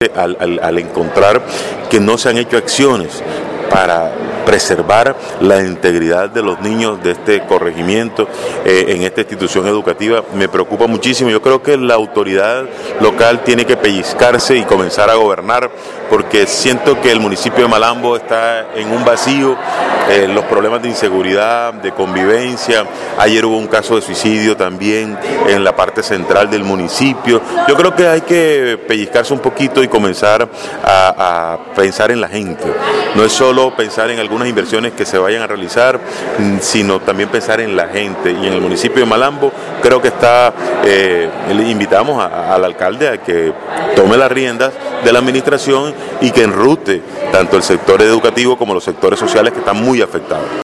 En Malambo, hay problemas serios de convivencia y la Alcaldía no ha actuado, dice el diputado Adalberto Llinás